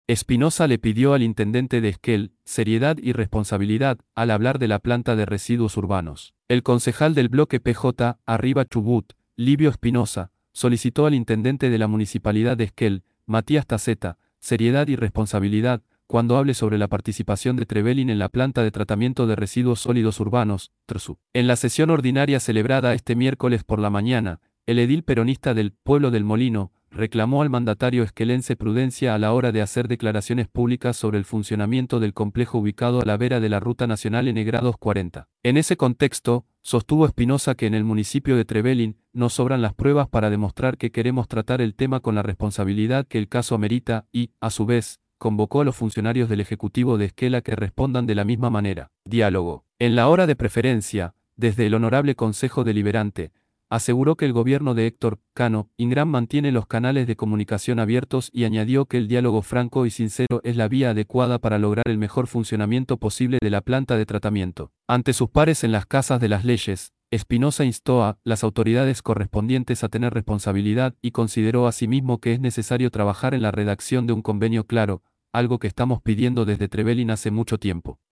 livio_espinoza_xv_sesion_2024.mp3